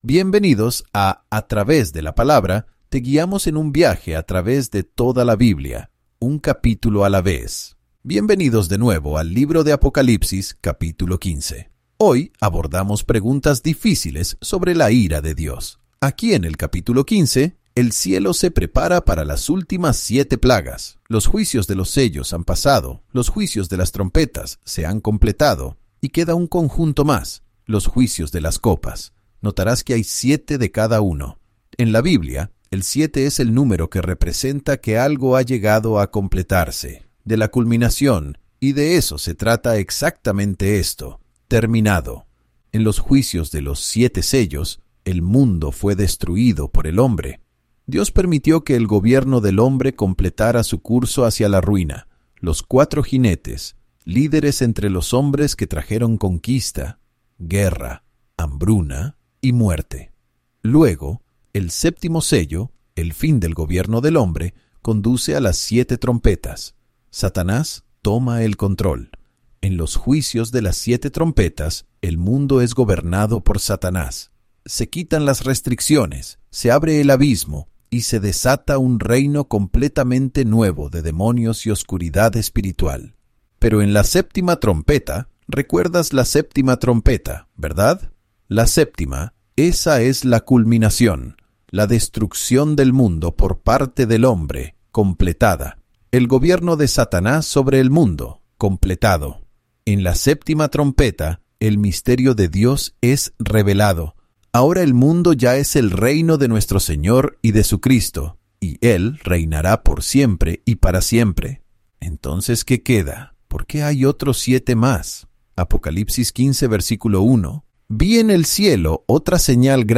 Divulgación Legal Para el beneficio de nuestros oyentes, TTW Bible Audio Guides (Guías de audio de “A Través de la Palabra”) utiliza tecnologías de IA para recrear las voces de los maestros en diferentes idiomas, con el apoyo de la supervisión humana y la garantía de calidad.